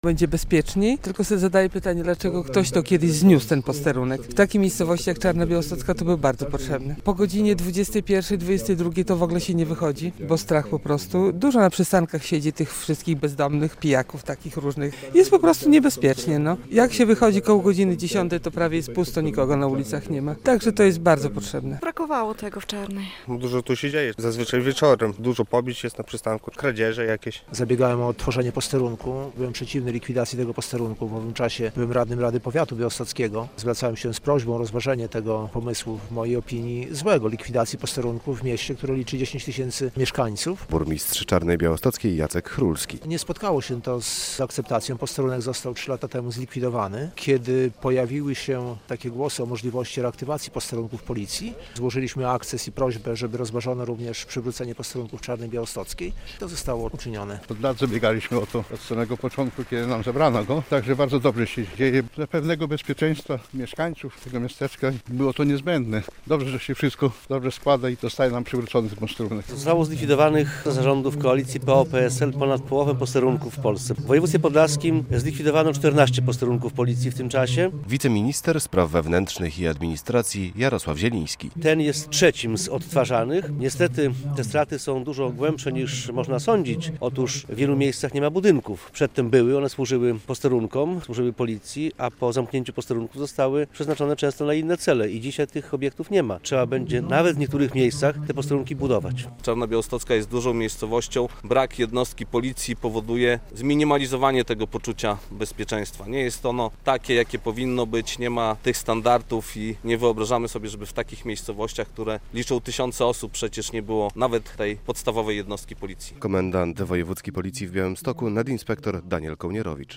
Otwarcie posterunku policji w Czarnej Białostockiej - relacja